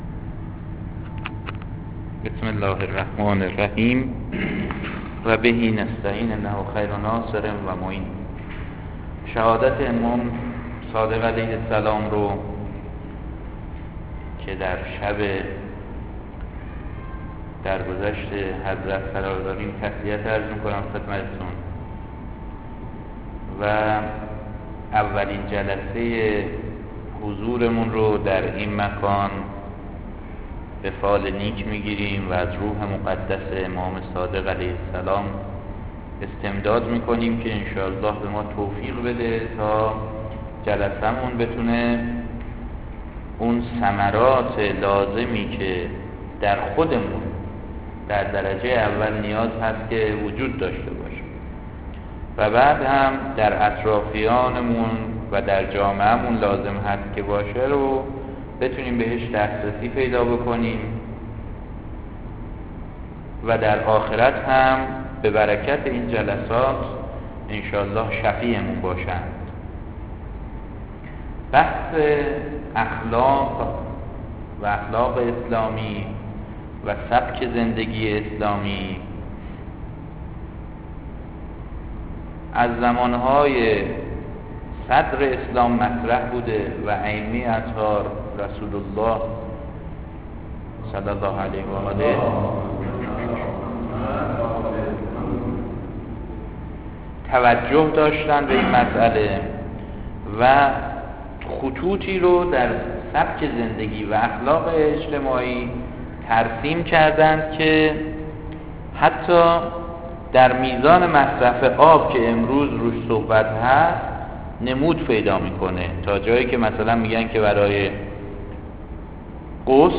ششمین جلسه درس اخلاق